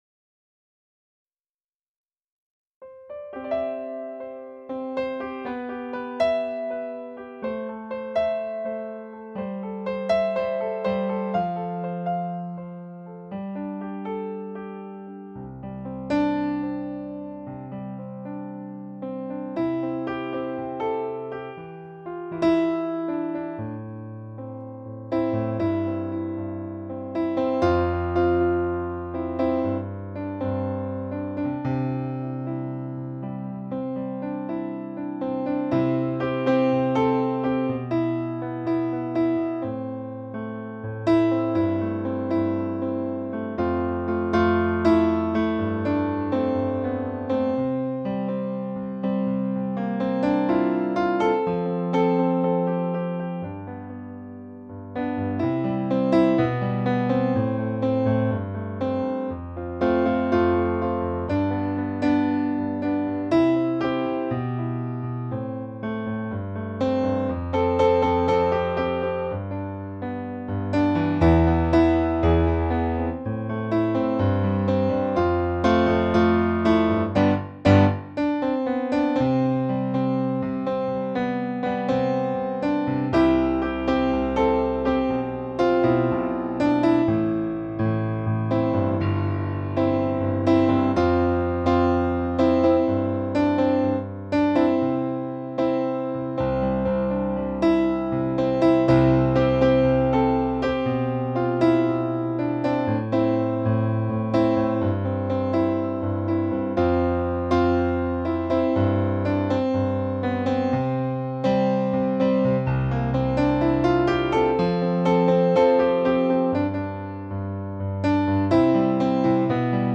Lika unika – pianokomp, sjung själv.